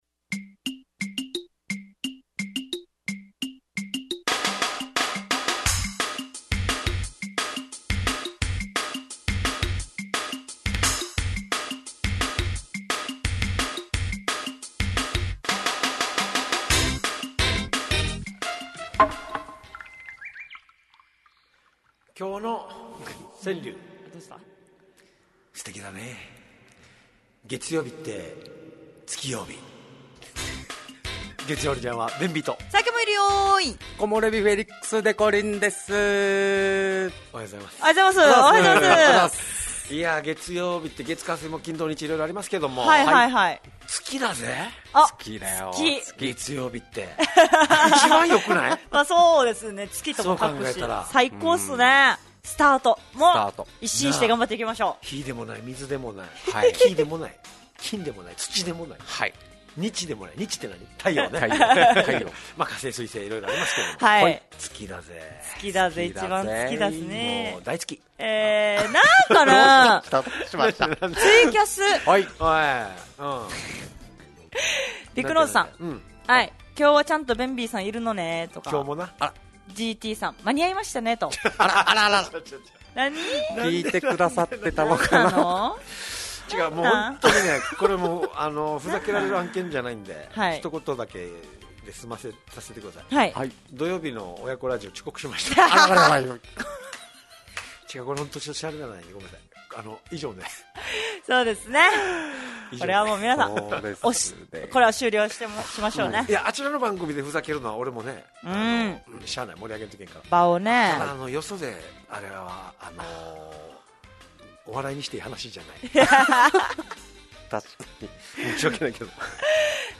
fm那覇がお届けする沖縄のお笑い集団・オリジンメンバー出演のバラエティ番組のオリジンアワー